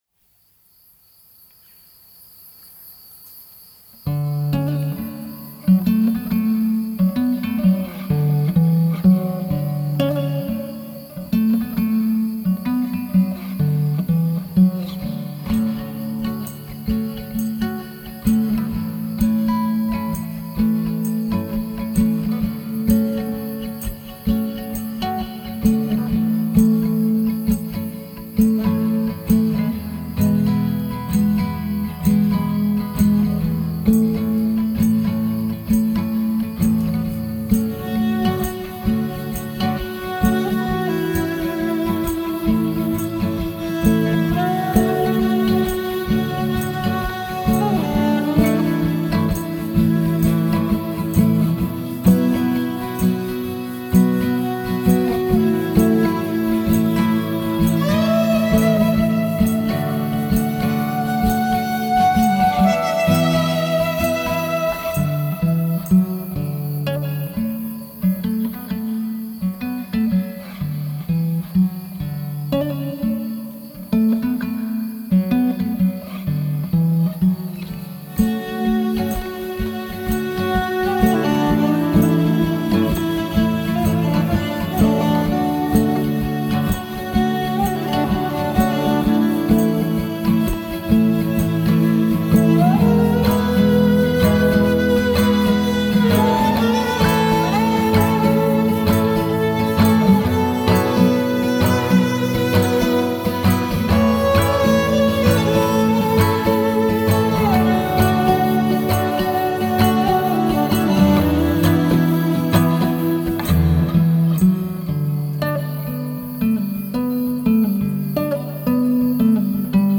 THE OUTSIDE SONG An Instrumental
on the guitar and tambourine. The chorus is provided by Kelsey Creek crickets outside on a starry night with violin backup